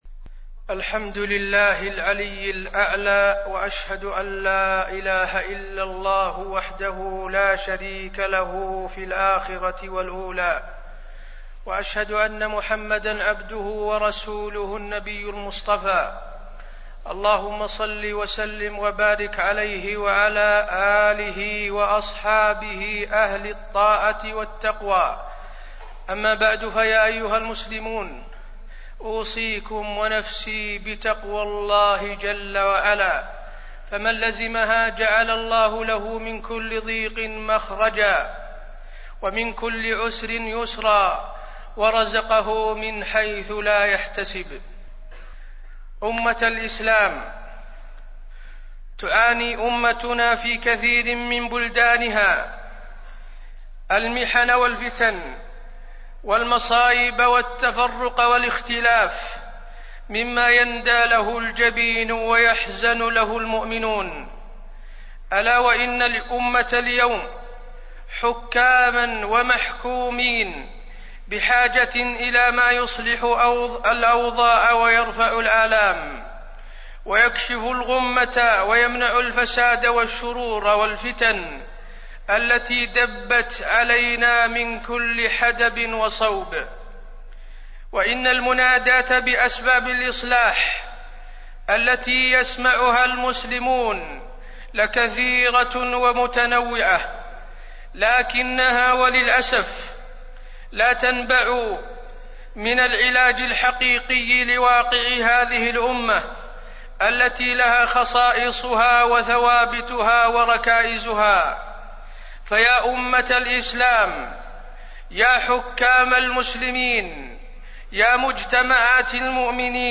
تاريخ النشر ١٧ صفر ١٤٣٢ هـ المكان: المسجد النبوي الشيخ: فضيلة الشيخ د. حسين بن عبدالعزيز آل الشيخ فضيلة الشيخ د. حسين بن عبدالعزيز آل الشيخ أسباب الهلاك في الدنيا والآخرة